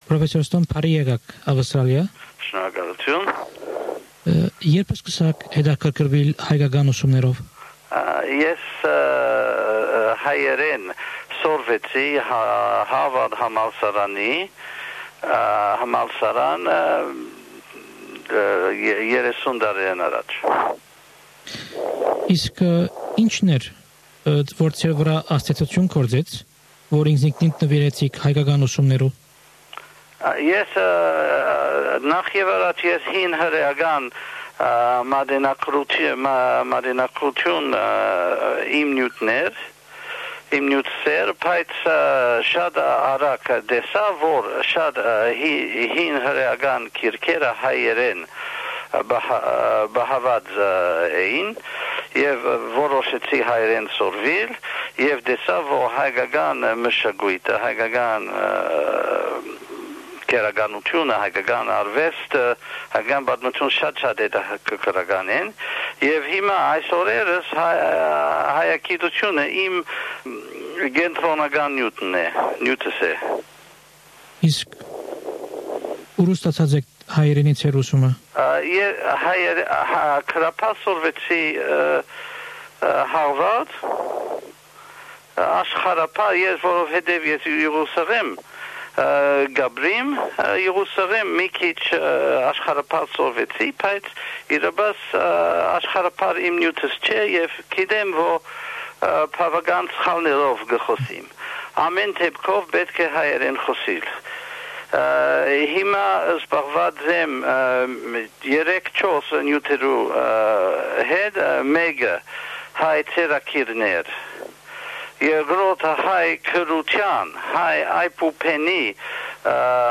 The interview is in Armenian.